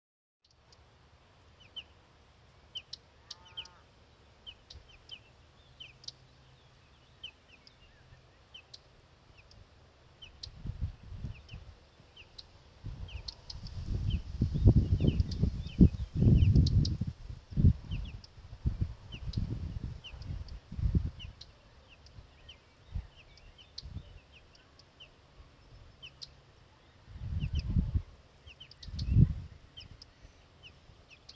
Птицы -> Дроздовые ->
луговой чекан, Saxicola rubetra
СтатусТерриториальное поведение